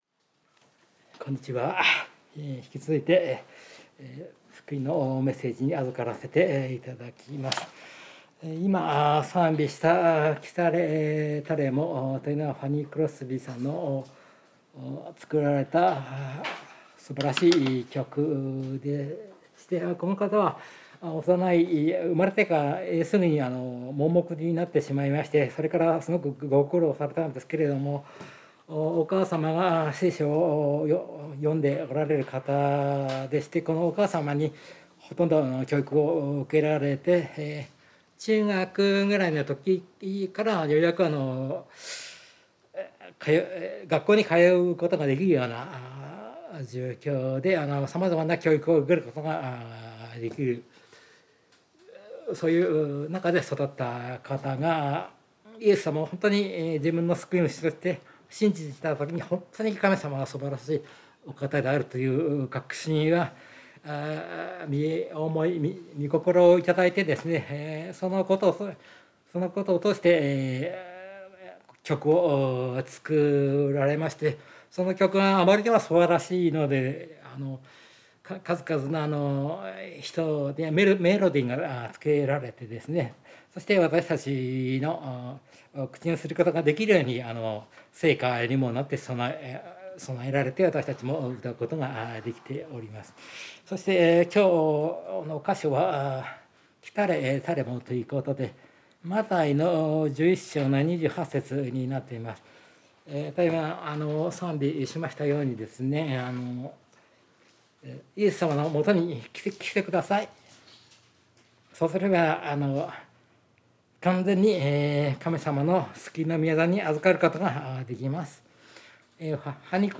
聖書メッセージ No.287